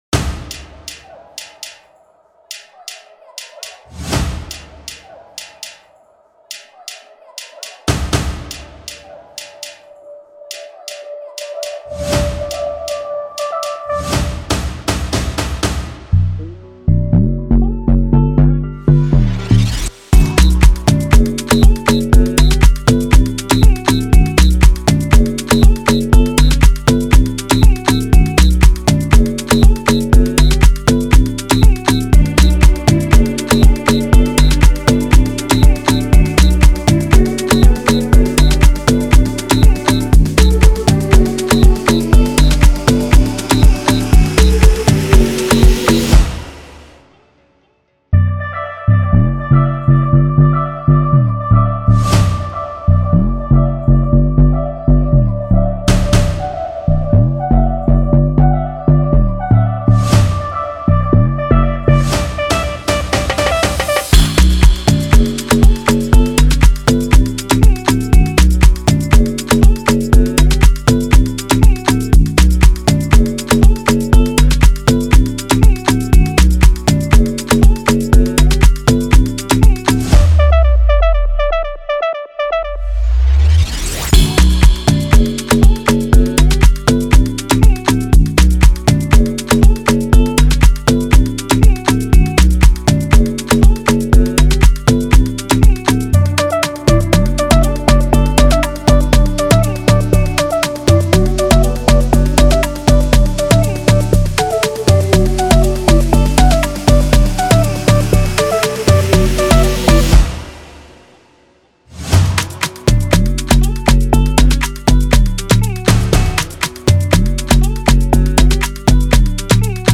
official instrumental
2025 in Dancehall/Afrobeats Instrumentals